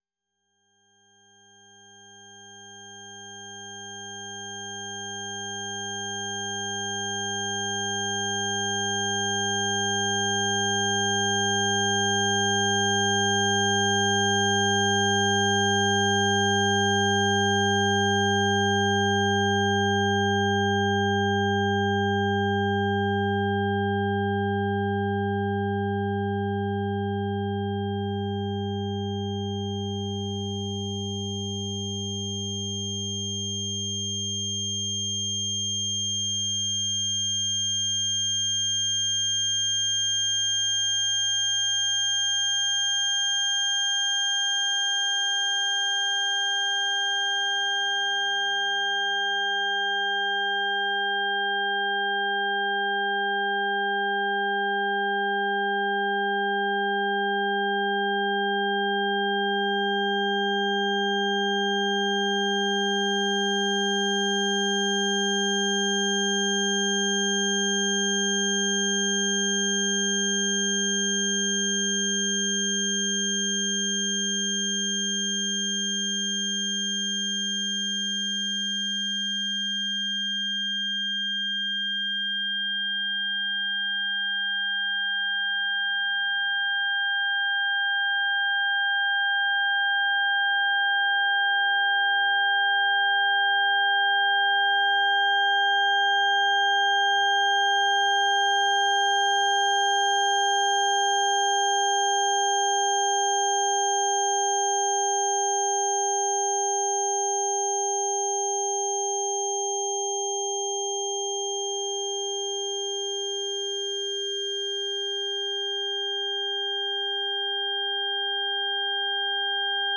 electroacoustic music
7-Kanal-Audio